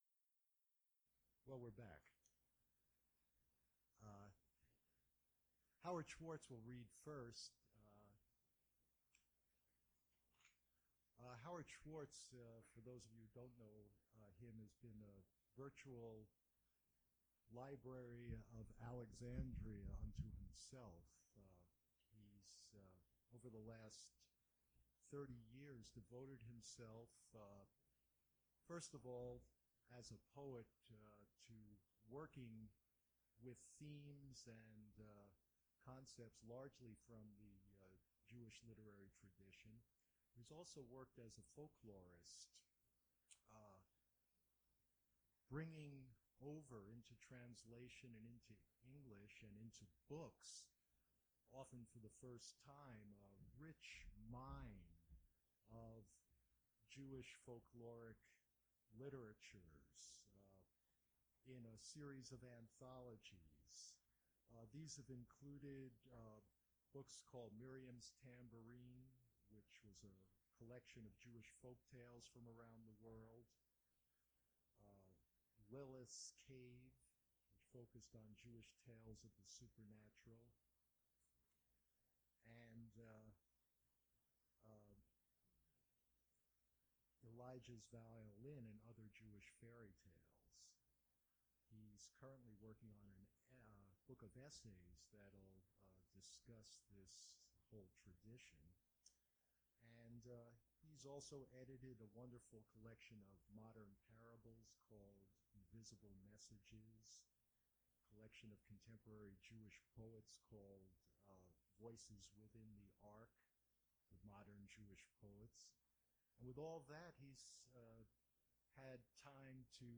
Poetry reading
(audio fades out at end)
(audio blips out in introduction)
mp3 edited access file was created from unedited access file which was sourced from preservation WAV file that was generated from original audio cassette. Language English Identifier CASS.784 Series River Styx at Duff's River Styx Archive (MSS127), 1973-2001 Note Removed instrumental intro and technical difficulties at the beginning.